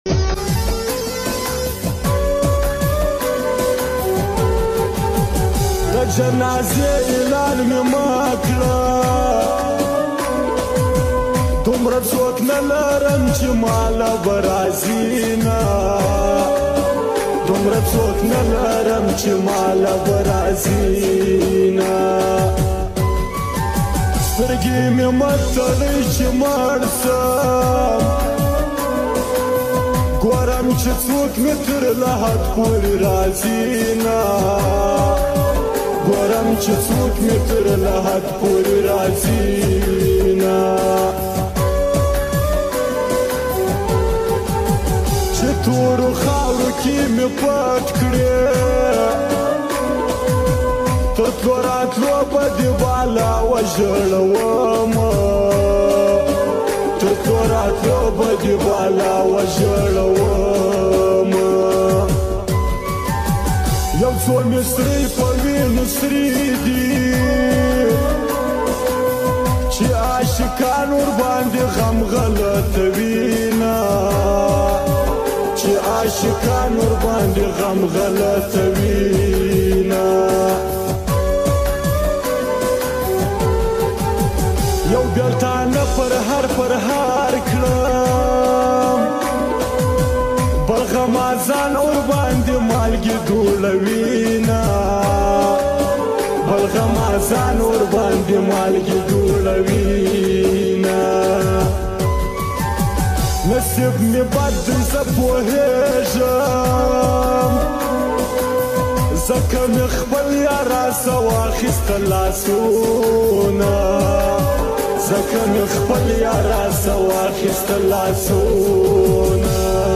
دہ۔جنازی۔علان۔می۔مہ۔کڑہ۔دم۔رہ💔۔سوکہ۔نہ۔لہ۔رم۔چی۔مالہ۔رازی۔پشتو۔غمجنی۔ٹپے۔